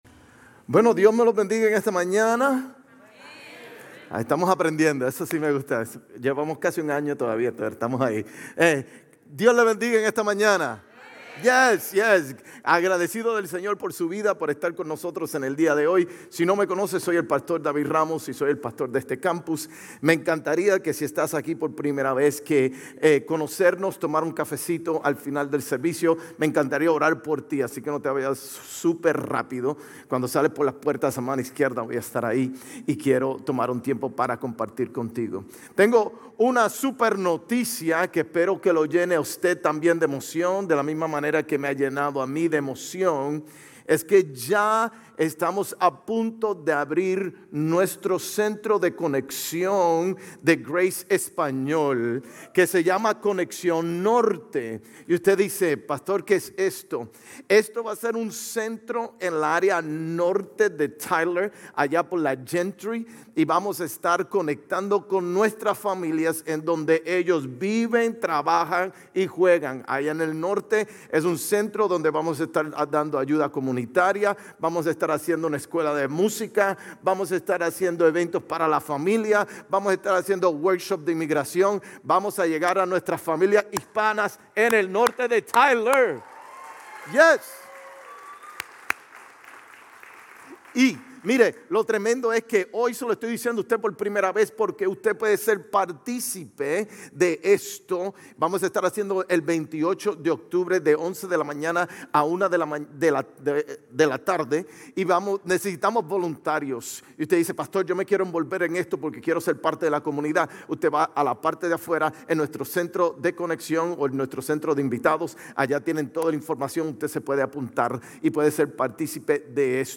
GCC-GE-October-1-Sermon.mp3